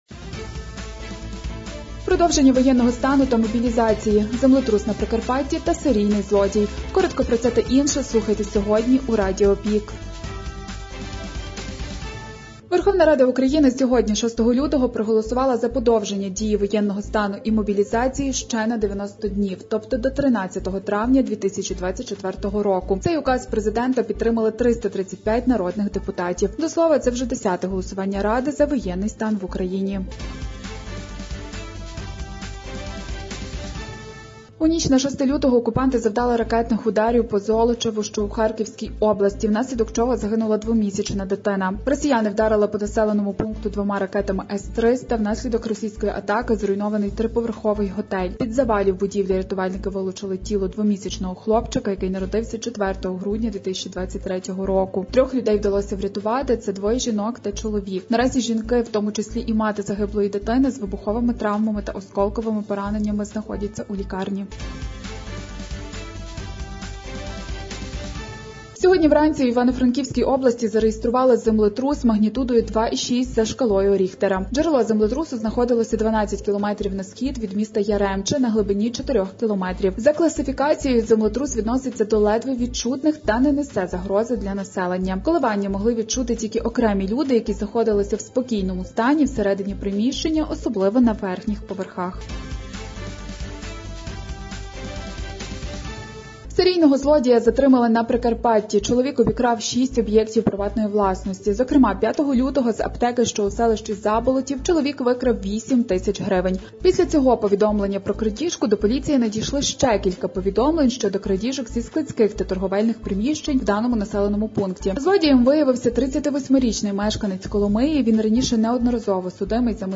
Актуальне за день у радіоформаті.